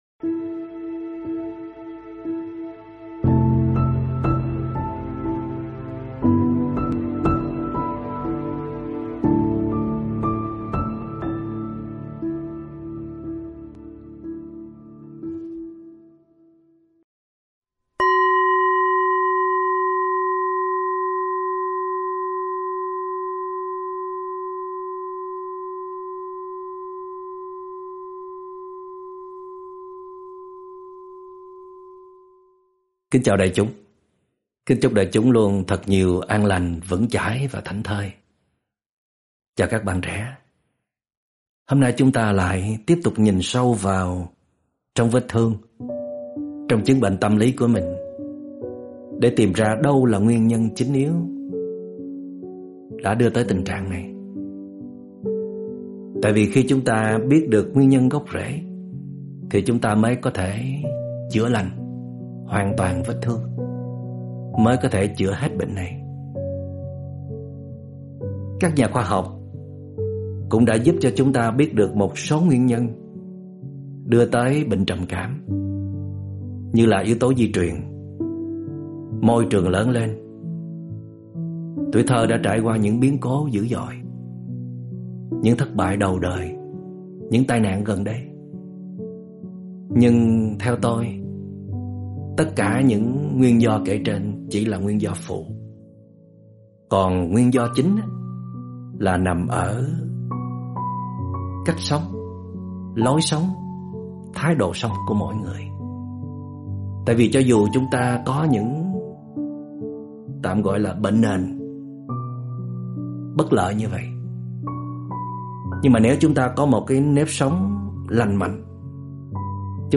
Mời quý phật tử nghe pháp âm Hãy chăm sóc trầm cảm như một con cún cưng được Trích từ Radio: Nâng dậy tâm hồn do TS. Thích Minh Niệm giảng